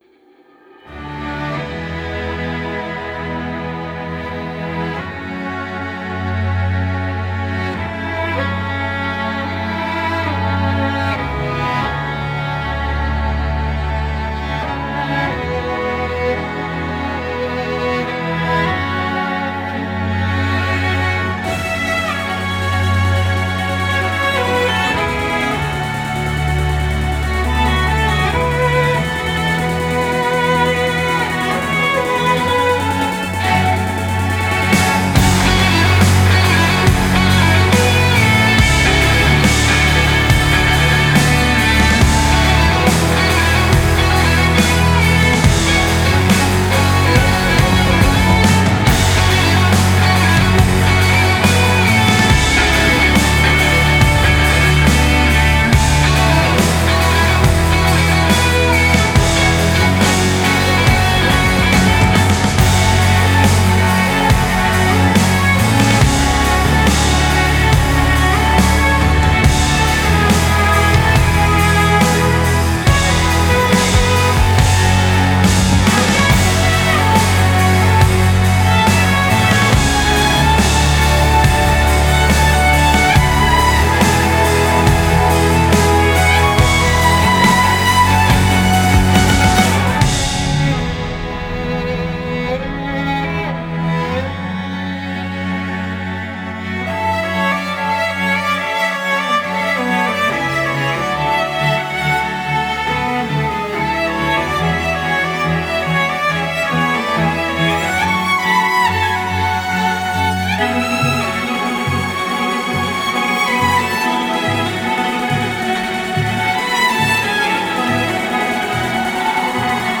электронный струнный квартет из Лондона, Великобритания
Genre: Electronic, Classical